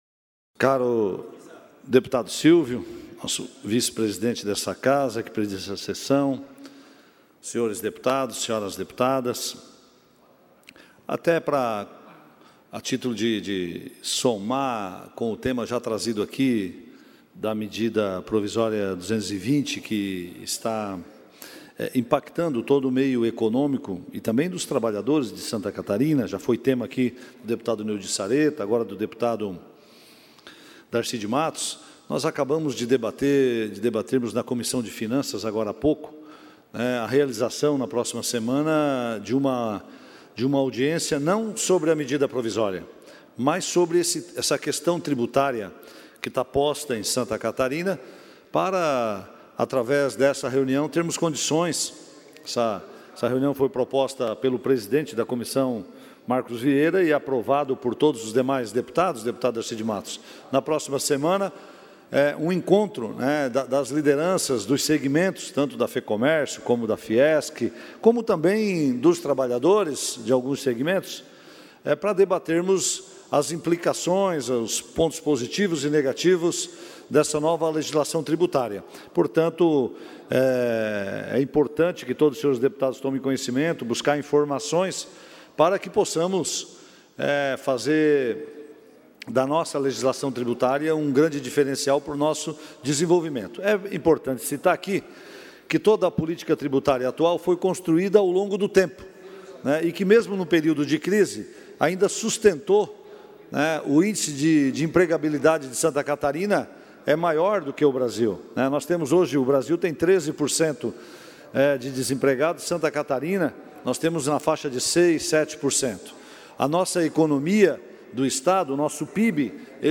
Pronunciamentos dos deputados na sessão da manhã desta quarta-feira (02)
Confira aqui a manifestação dos parlamentares em tribuna durante sessão ordinária da manhã desta quarta-feira (02):